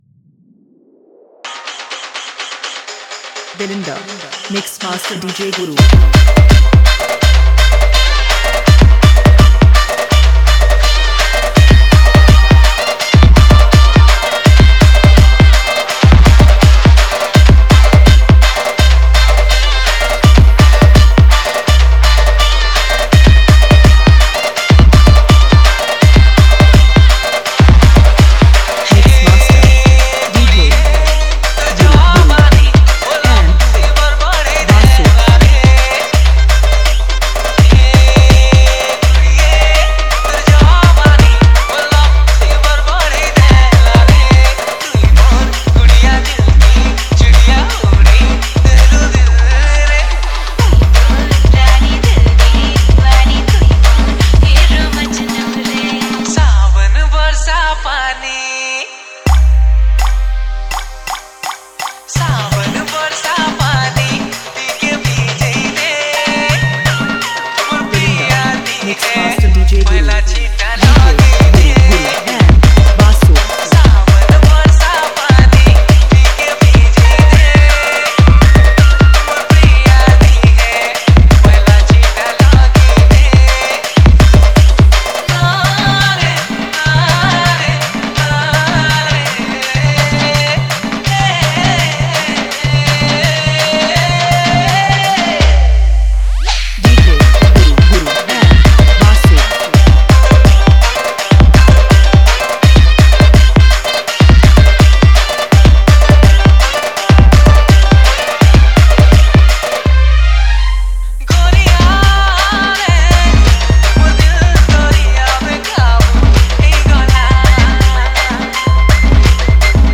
Dj Songs